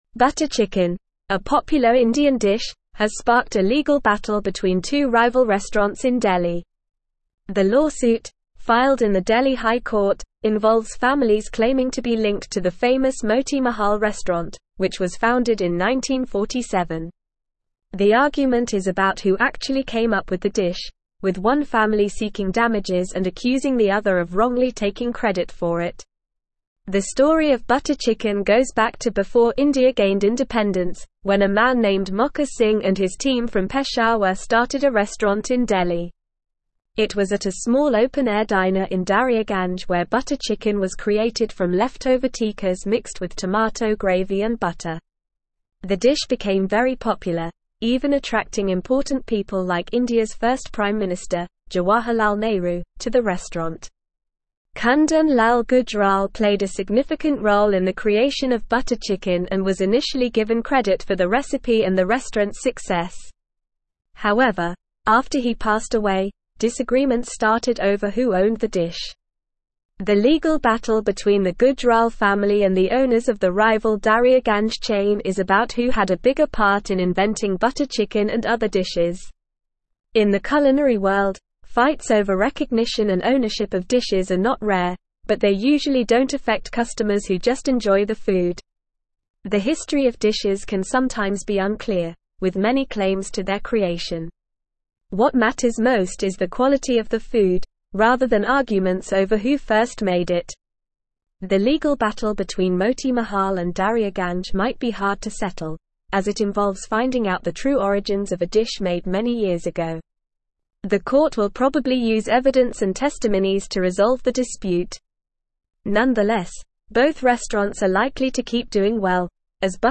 Normal
English-Newsroom-Upper-Intermediate-NORMAL-Reading-Butter-Chicken-Origins-Delhi-Restaurants-in-Legal-Battle.mp3